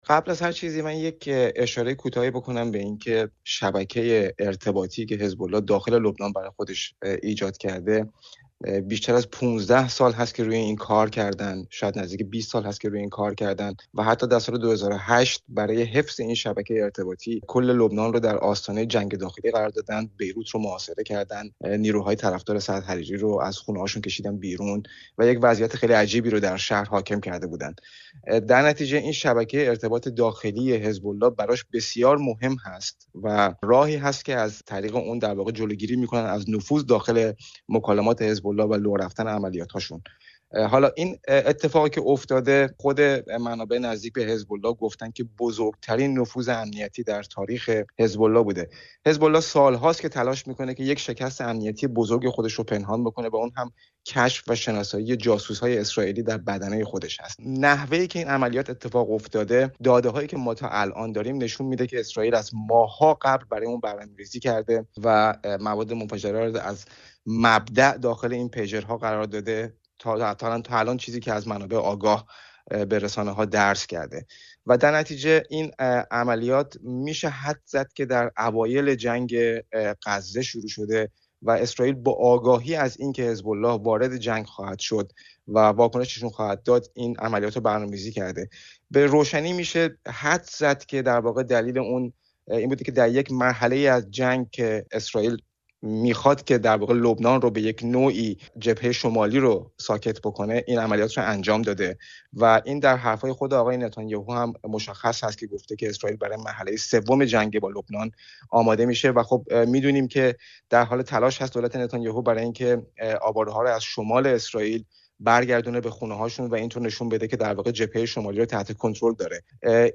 گفتگو کردیم.